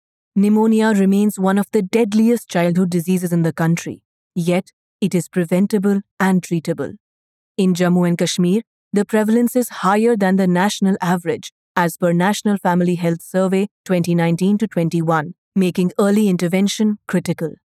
english Neutral and hindi female voice artist from Delhi